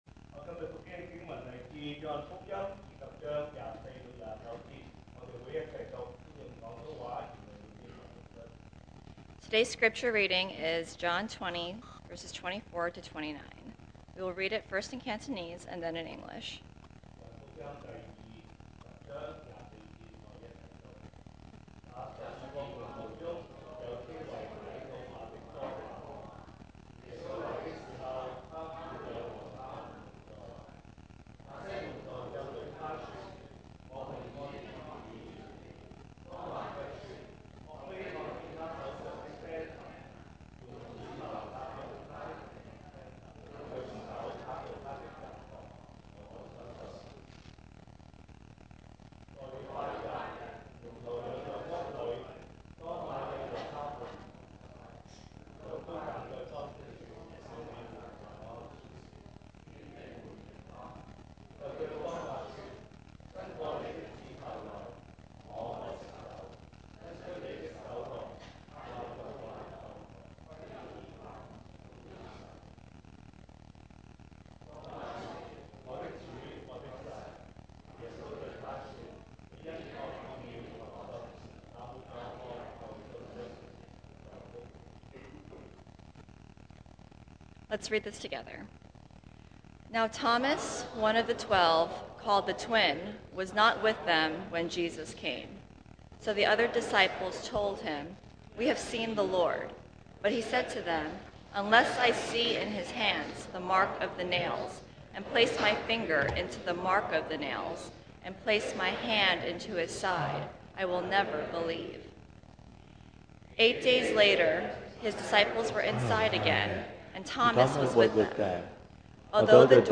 2023 sermon audios